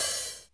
Open Hats
OpenHH Rnb 1.wav